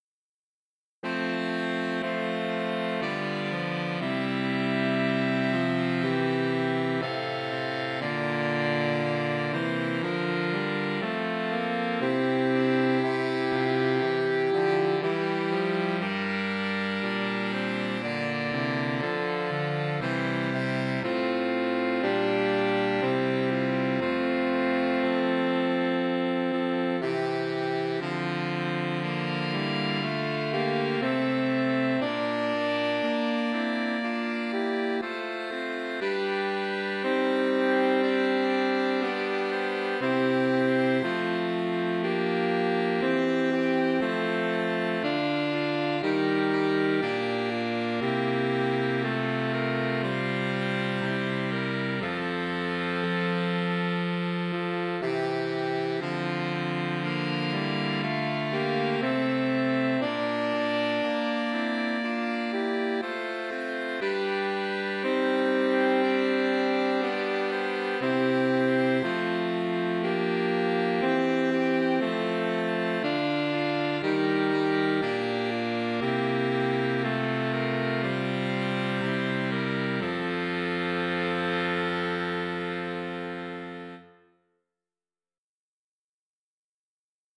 Saxophone Quintets
mp3 SCORE The Silver Swan [Orlando Gibbons] 2 SATTB, SAATB, AATTB, or AAATB. Slow, mournful madrigal.